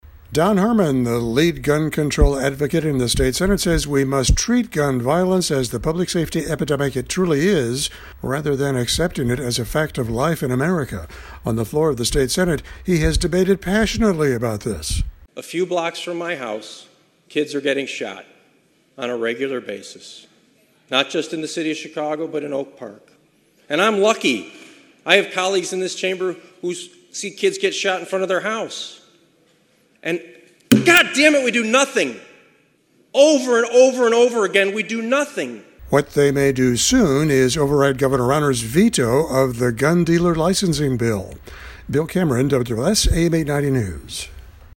On the floor of the senate, he has debated passionately for this.